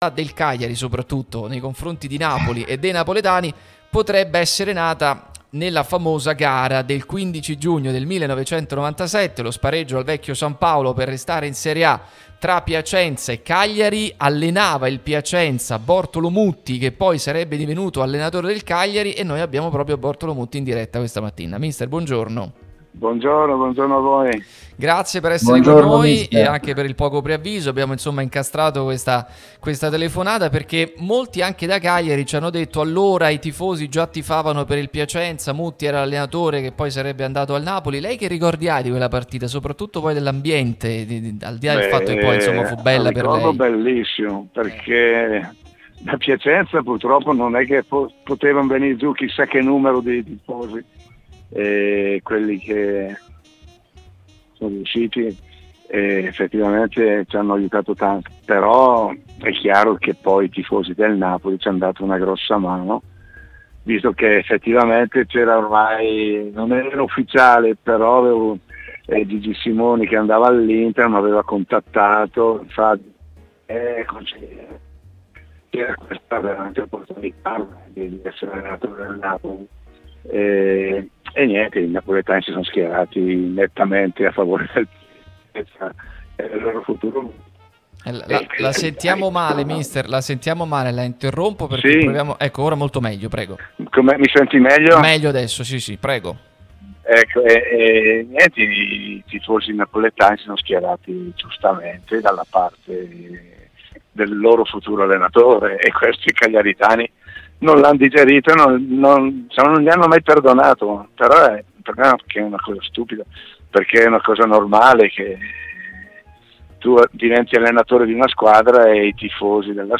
Proprio Bortolo Mutti è intervenuto su Radio Tutto Napoli, l'unica radio tutta azzurra e live tutto il giorno, che puoi seguire sulle app gratuite (per Iphone o per Android, Android Tv ed LG), in DAB o qui sul sito anche in video.